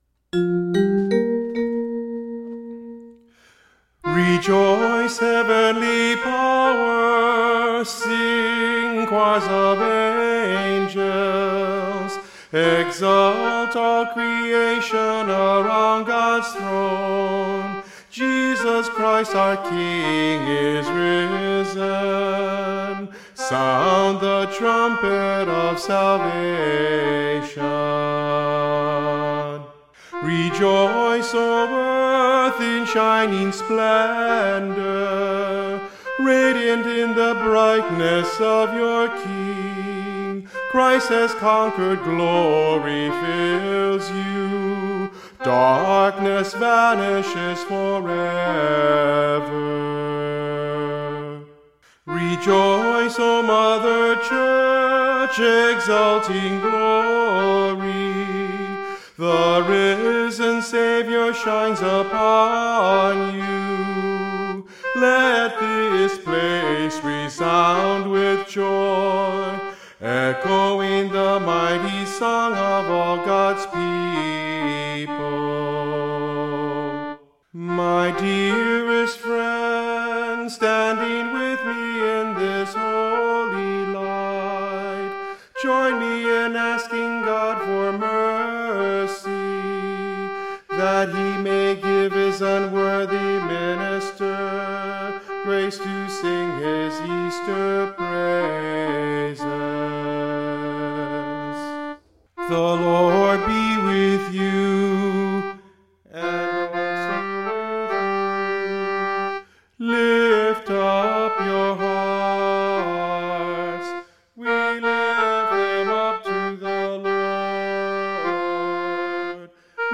Soundclips:|| Midi, Mp3 as available (contact me if needed)  All of Exultet sung:
Voice | Downloadable